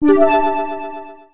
メール音やSMSの通知音。